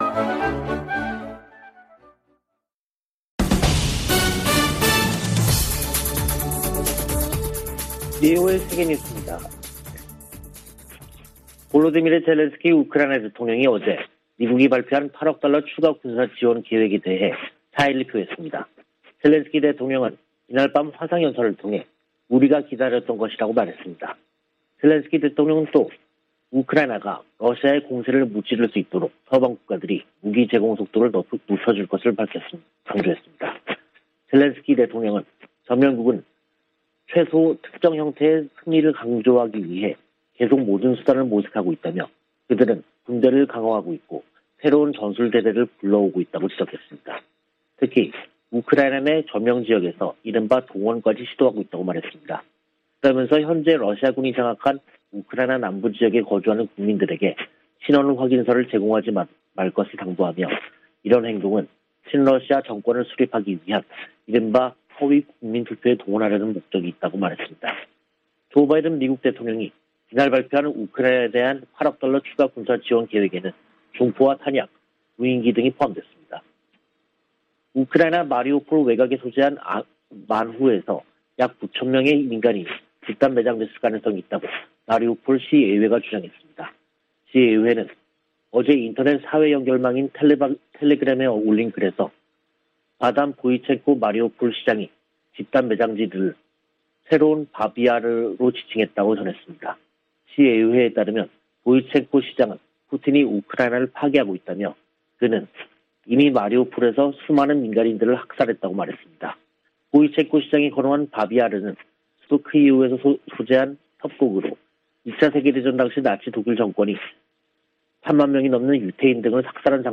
VOA 한국어 간판 뉴스 프로그램 '뉴스 투데이', 2022년 4월 22일 3부 방송입니다. 유럽연합(EU)이 북한의 잇단 미사일 발사에 대응해 북한 개인 8명과 기관 4곳을 독자제재 명단에 추가했습니다. 미 국무부는 북한의 도발에 계속 책임을 물리겠다고 경고하고, 북한이 대화 제안에 호응하지 않고 있다고 지적했습니다. 문재인 한국 대통령이 김정은 북한 국무위원장과 남북 정상선언의 의미를 긍정적으로 평가한 친서를 주고 받았습니다.